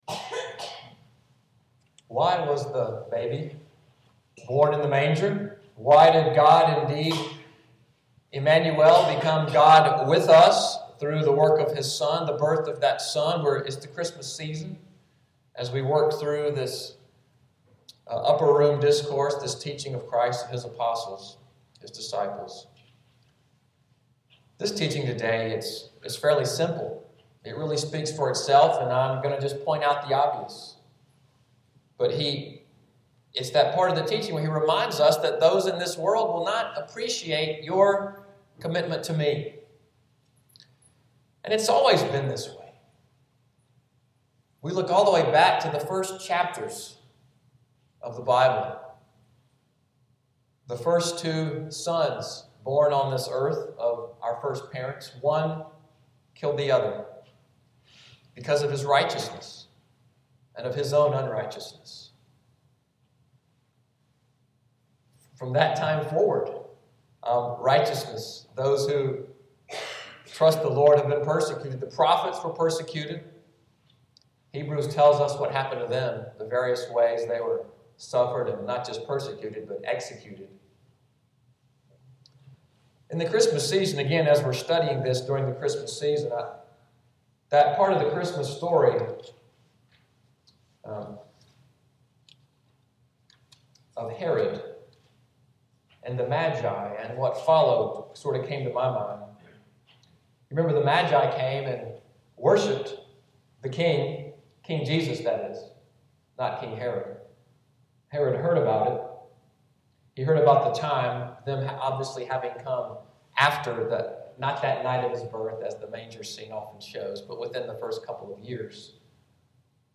Audio from the sermon, “The Coming Persecution,” Dec. 15, 2013.